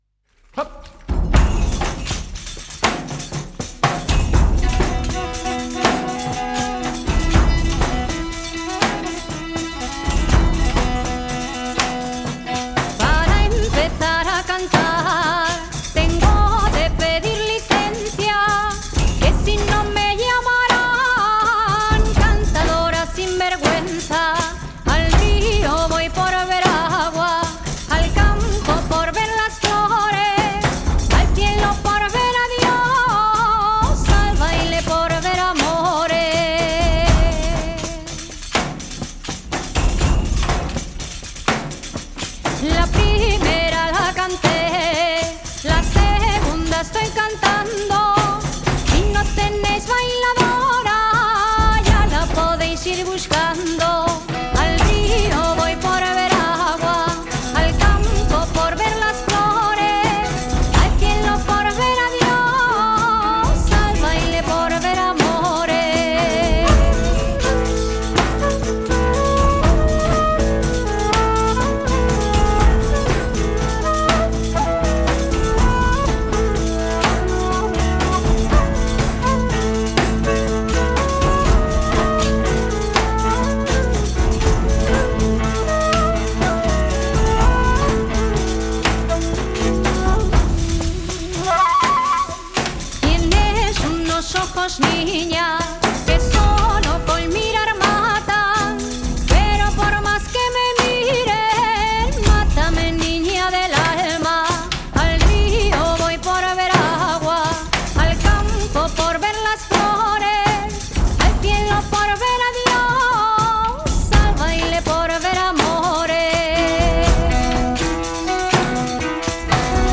dos referentes esenciales de la música de raíz europea.